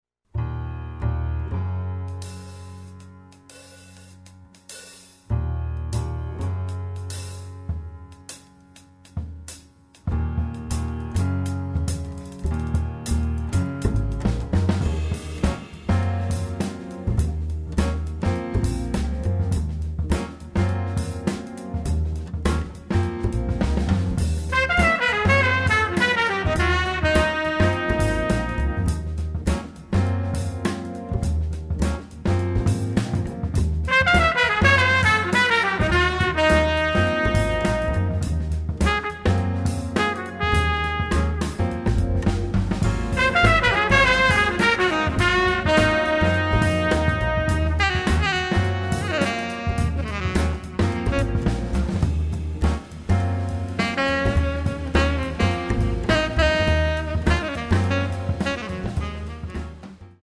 trumpet, keyboards